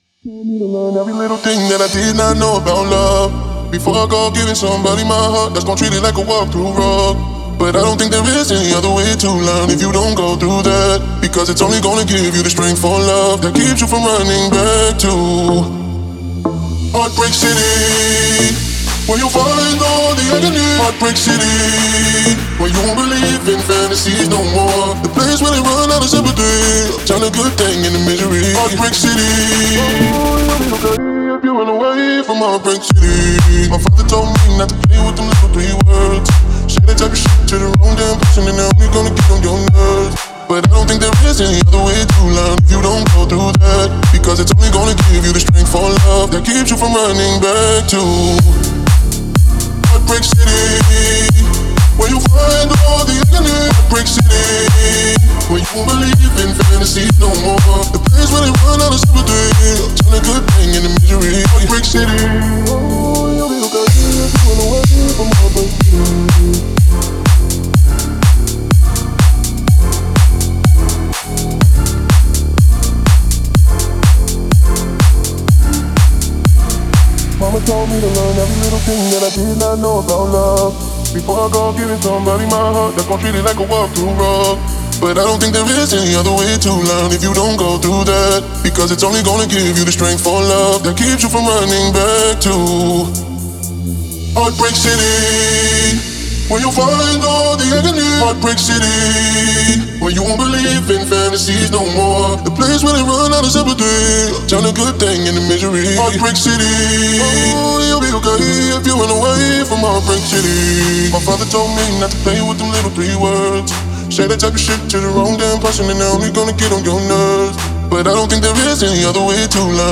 это проникновенная композиция в жанре поп с элементами R&B